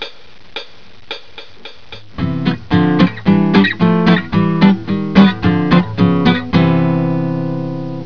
SWING CHORDS - page 2 of 2
The following chord form has the Root note on the fifth (A) string, the 3rd on the fourth (D) string, and the 7th on the third (G) string.